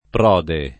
pr0de] s. m. (ant.